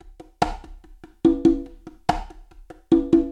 PERCU
Percu syncopée
Straight / 72 / 1 mes
CONGA1 - 72.mp3